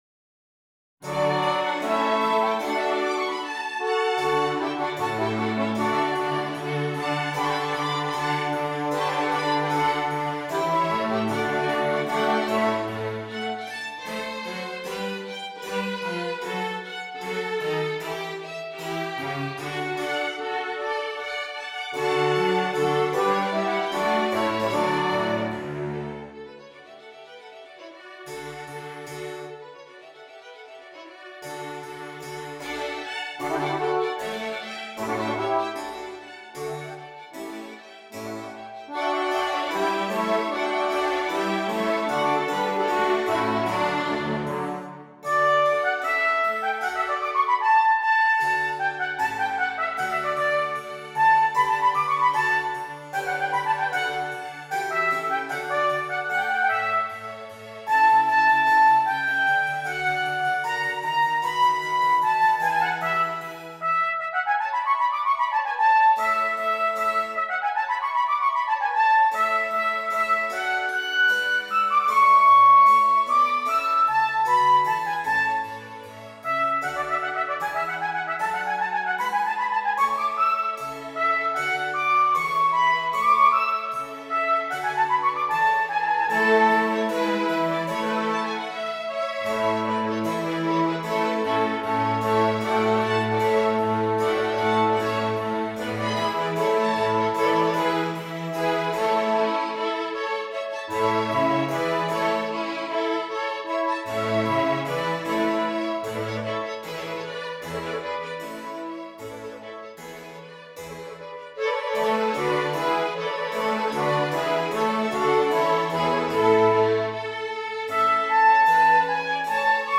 Trumpet and Strings